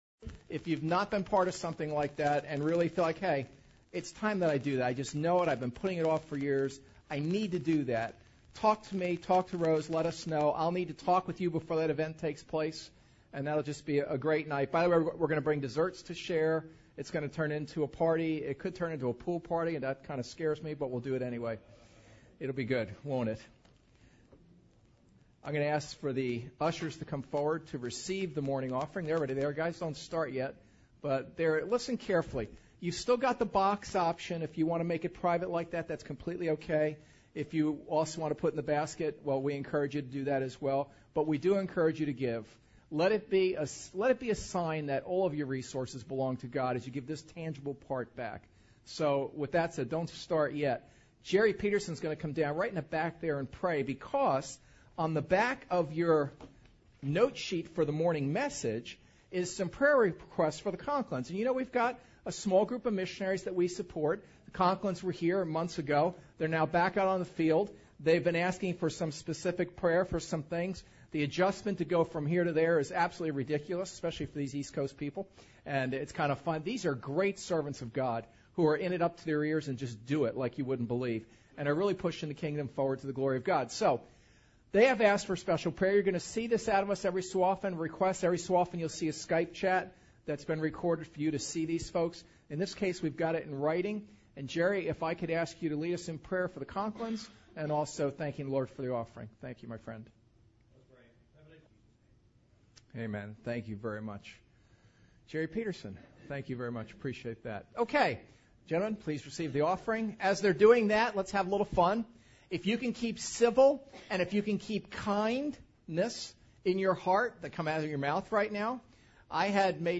DEUTERONOMY 1:19-33 Service Type: Sunday Service 'JUST DO IT!’